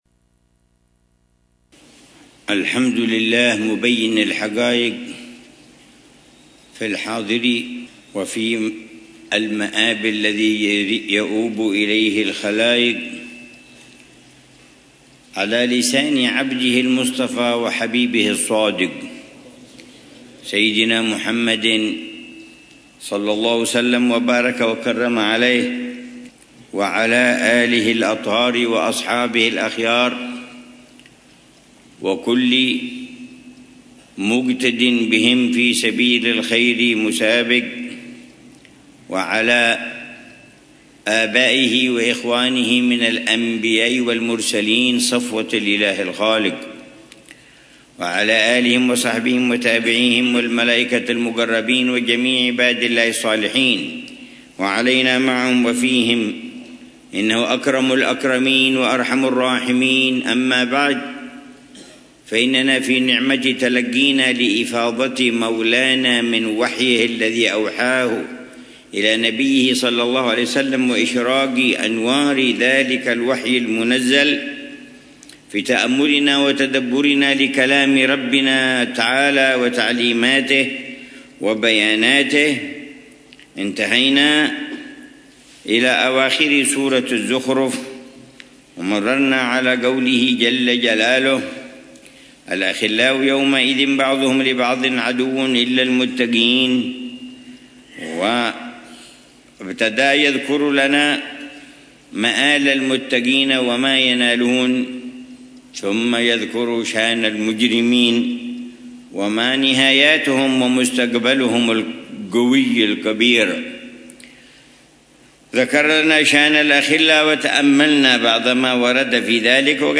الدرس التاسع من تفسير العلامة عمر بن محمد بن حفيظ للآيات الكريمة من سورة الزخرف، ضمن الدروس الصباحية لشهر رمضان المبارك من عام 1446هـ